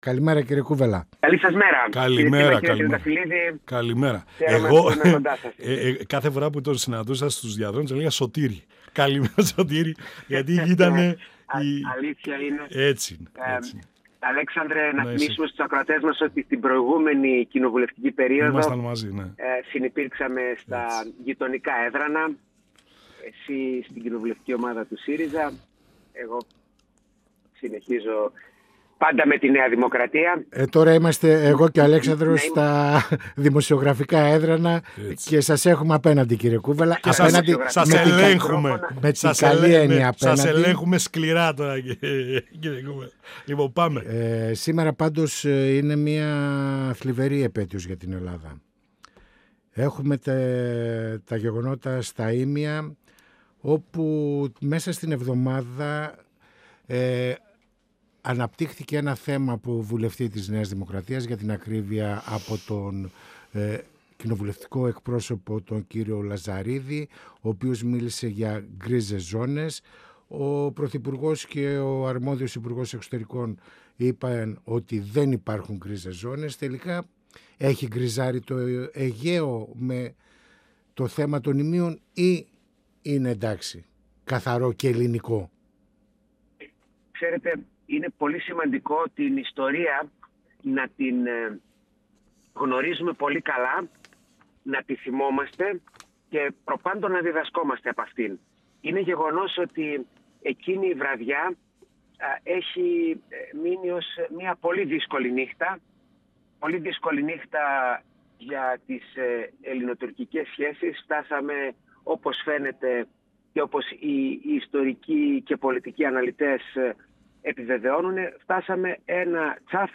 Στις πολιτικές εξελίξεις, στα εθνικά θέματα και τις σχέσεις με την Τουρκία, καθώς και την επέτειο των Ιμίων αναφέρθηκε ο Βουλευτής της ΝΔ Δημήτρης Κούβελας, μιλώντας στην εκπομπή «Πανόραμα Επικαιρότητας» του 102FM της ΕΡΤ3.
Συνεντεύξεις